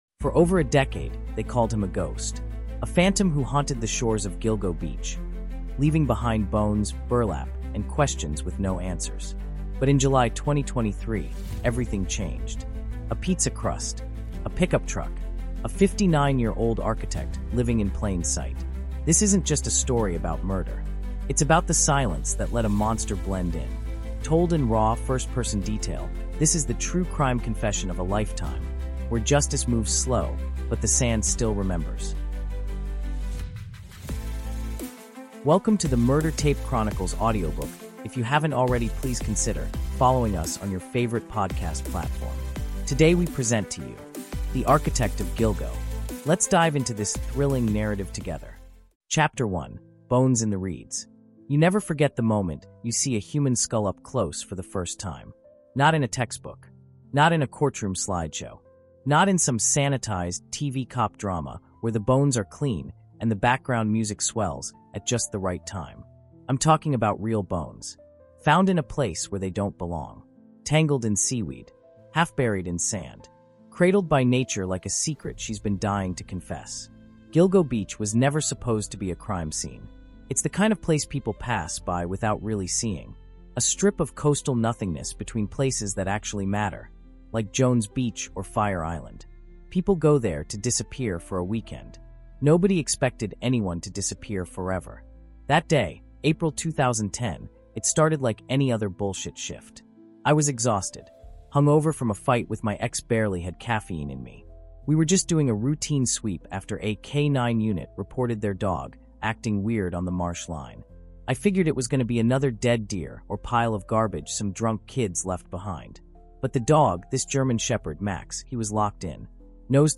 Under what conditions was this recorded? Featuring 911 call echoes, survivor testimony, and chilling crime scene details, this story digs deeper than headlines ever could.